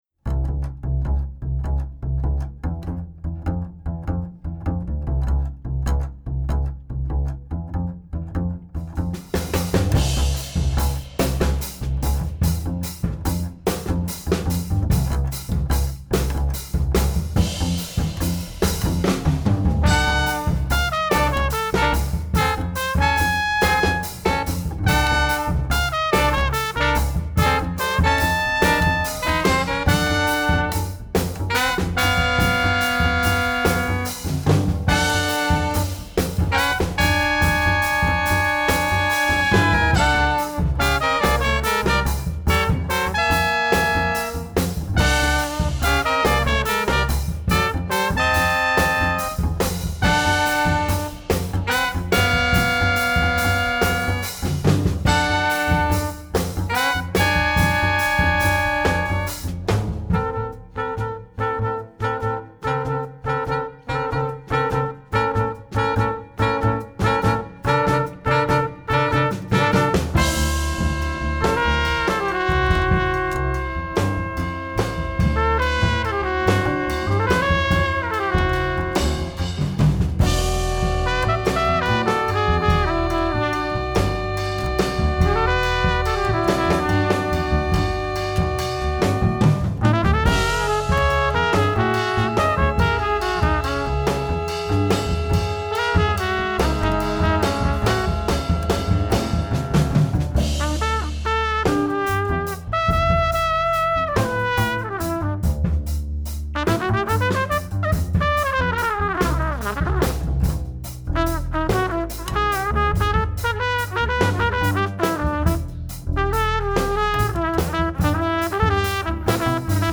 trumpet
alto saxophone
trombone
double bass
drums